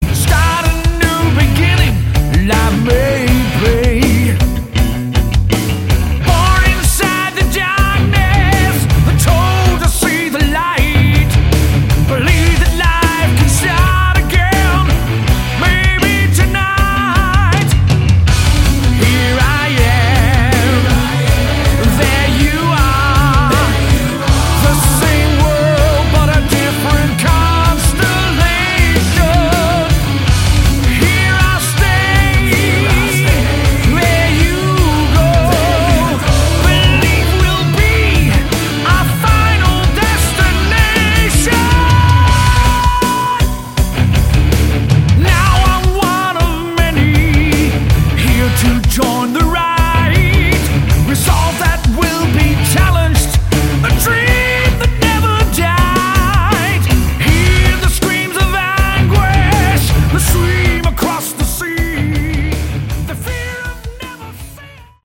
Category: Melodic Metal
guitar, vocals
bass, vocals
drums
lead vocals